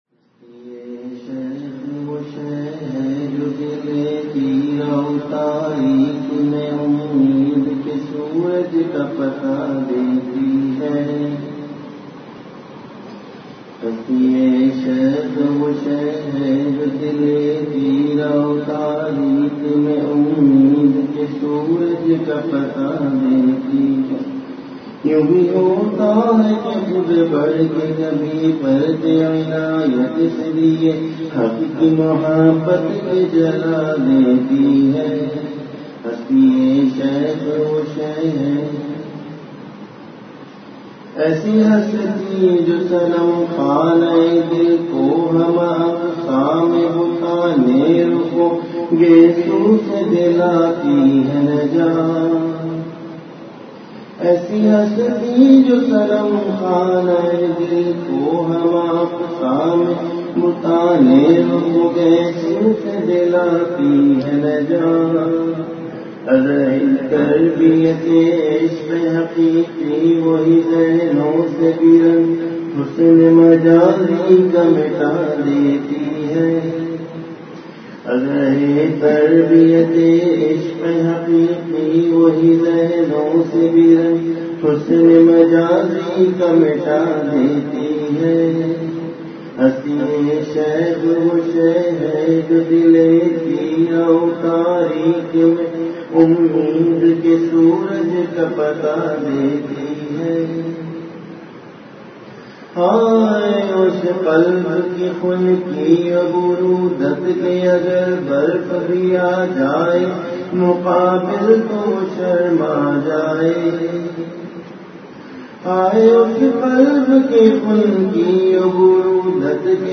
Majlis-e-Zikr